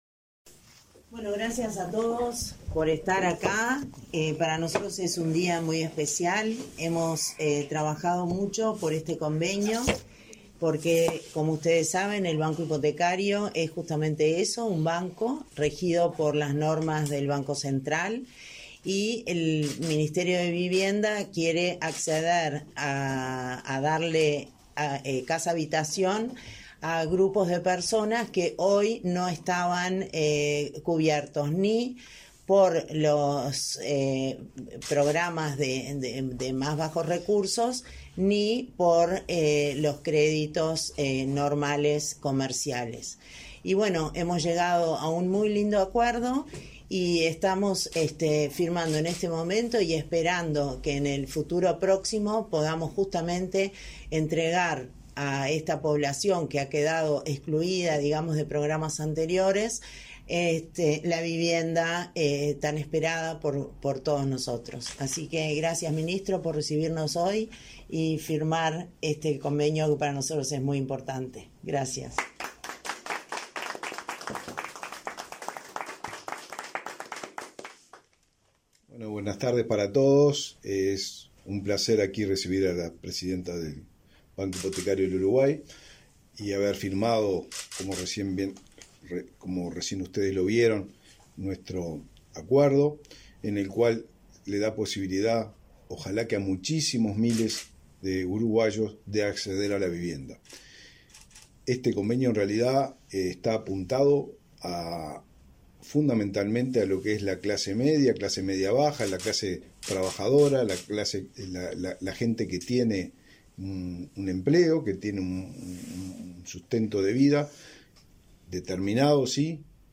Conferencia de prensa por firma de convenio entre el MVOT y el BHU
El Ministerio de Vivienda y Ordenamiento Territorial (MVOT) y el Banco Hipotecario del Uruguay (BHU) acordaron, este 21 de junio, aumentar hasta el 95 % el monto de los préstamos otorgados para acceder a inmuebles promovidos por el programa Entre Todos, Sueños en Obra. Participaron del evento el ministro Raúl Lozano y la presidenta del BHU, Casilda Echeverría.